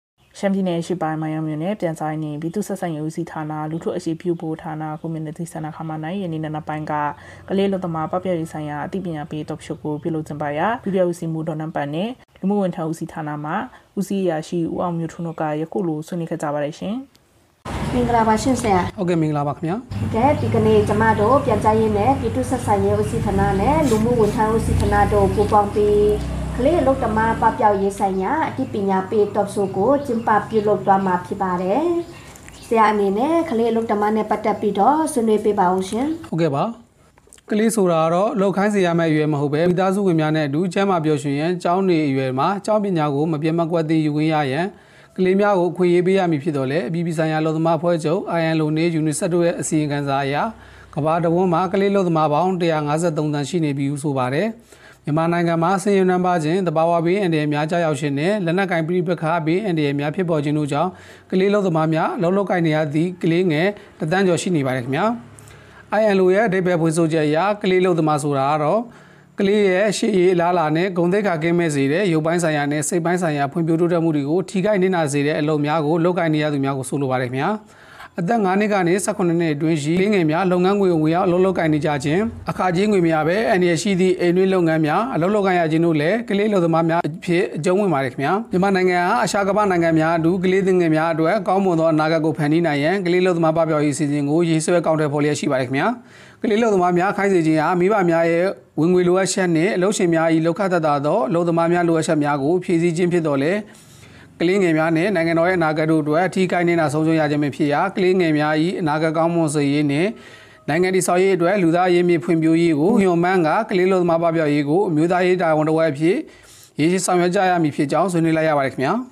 မိုင်းယောင်းမြို့၌ ကလေးအလုပ်သမားပပျောက်ရေးဆိုင်ရာ အသိပညာပေး Talk Show ကျင်းပ မိုင်းယောင်း စက်တင်ဘာ ၁၀